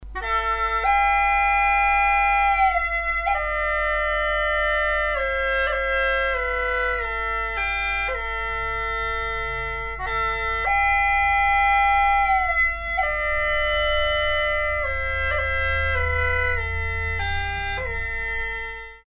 Хулуси С (пластик)
Хулуси С (пластик) Тональность: C
Хулуси - китайский язычковый инструмент.
Звучание мягкое и мелодичное.
Диапазон одна октава, строй - диатоника без 7-ой ступени.